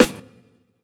Snares
SJK_SNR.wav